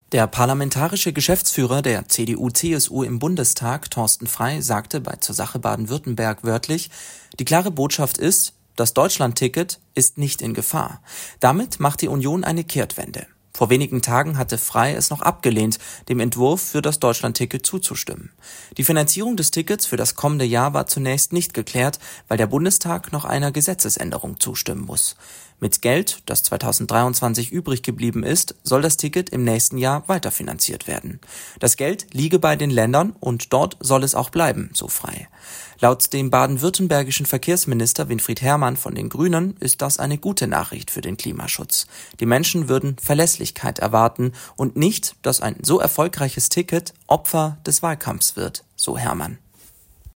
Thorsten Frei (CDU) bei "Zur Sache Baden-Württemberg"